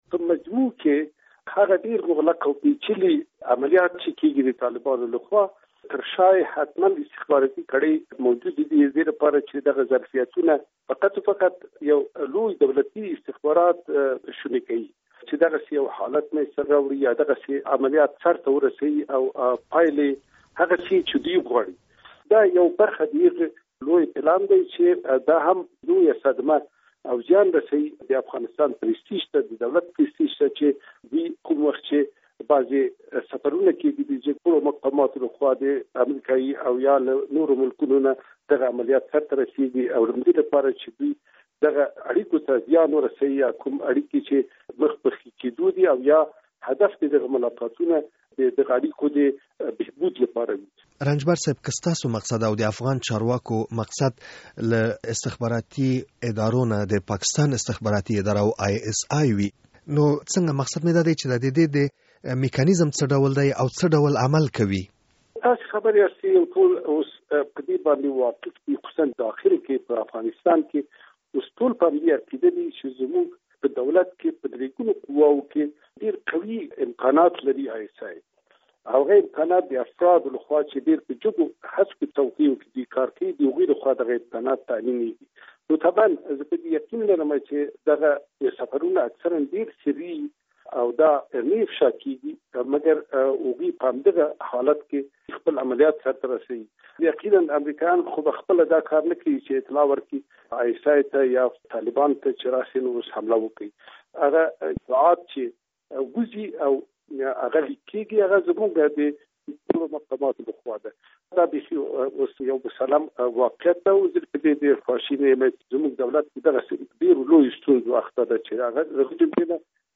له کبیر رنجبر سره مرکه